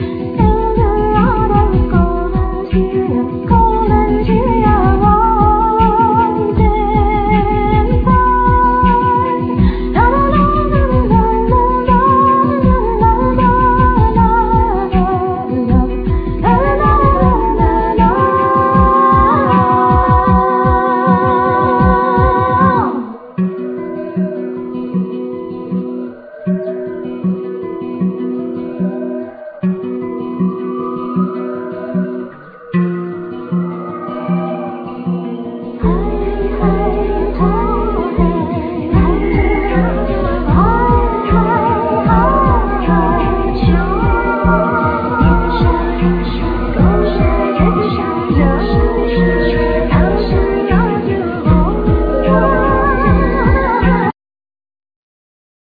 Electronics,Sampler,Voices
Violin
Daouli